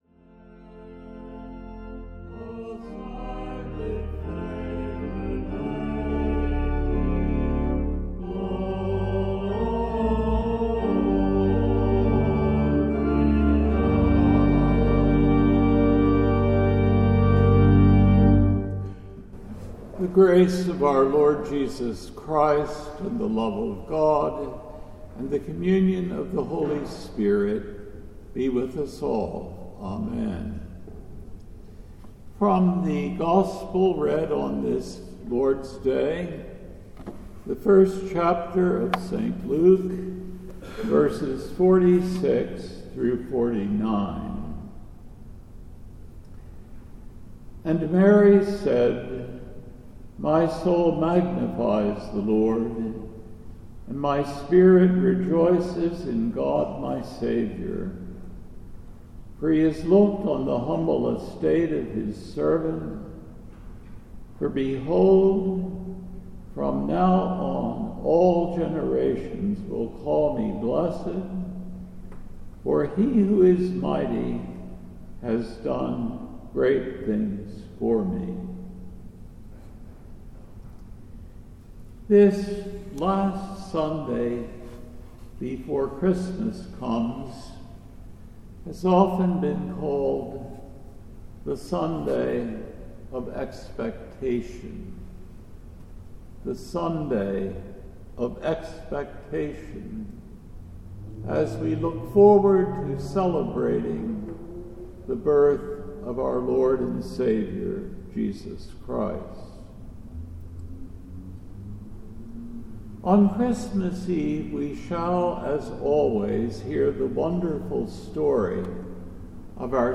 The Fourth Sunday in Advent December 22, 2024 AD